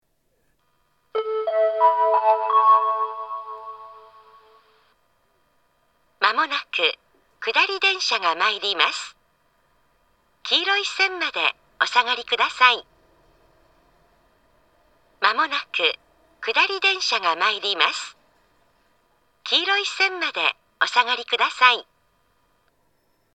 仙石型（女性）
接近放送
仙石型女性の接近放送です。